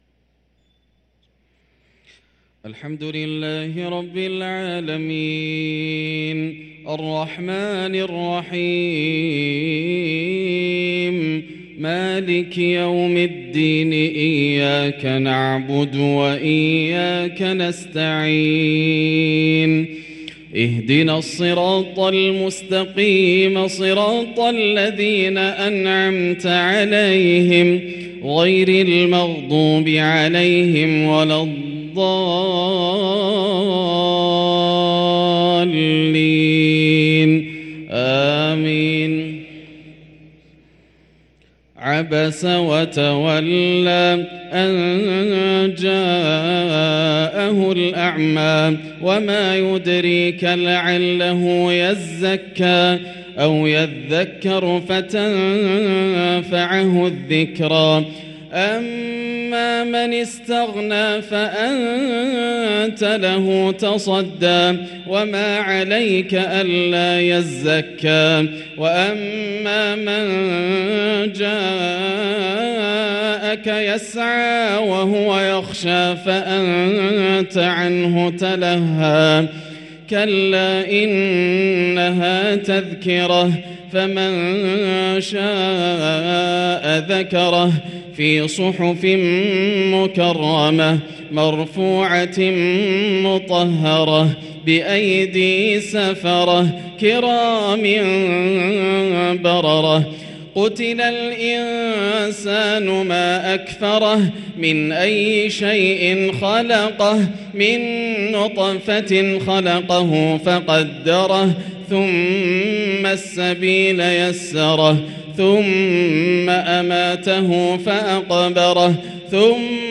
صلاة العشاء للقارئ ياسر الدوسري 21 رجب 1444 هـ